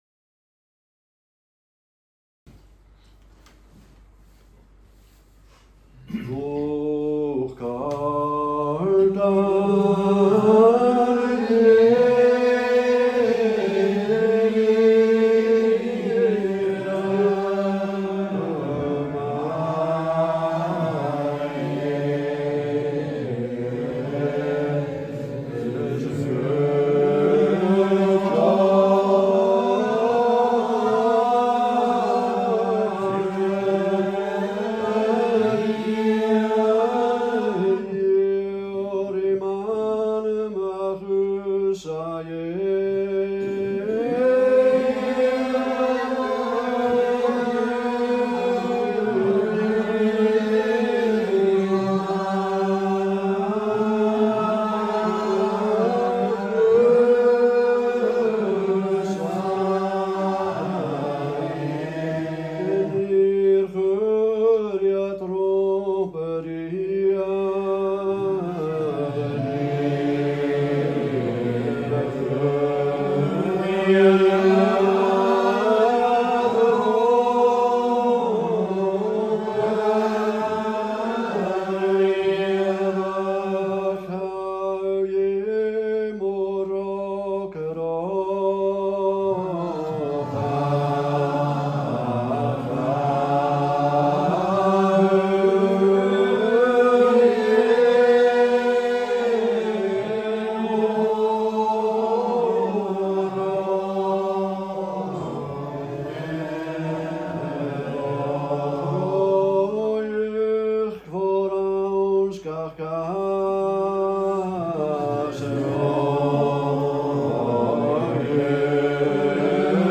Gaelic Psalmody - class 3